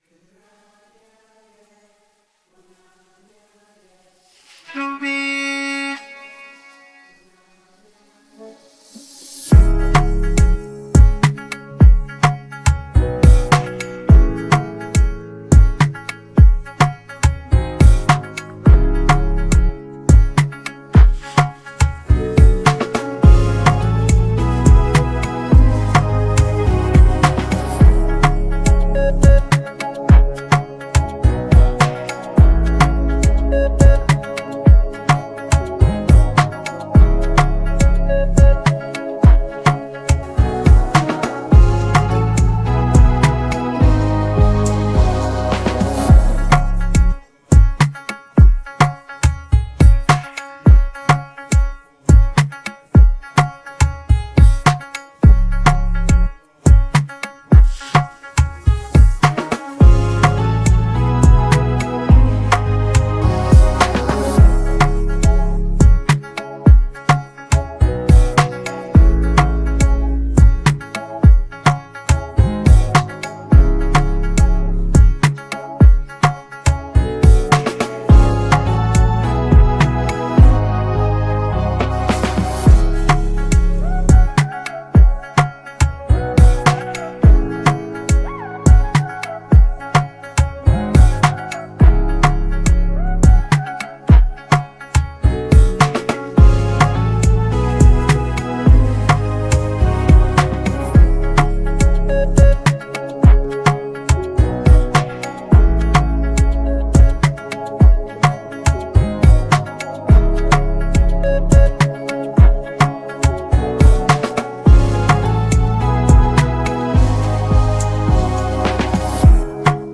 Afro popAfro trapGhana music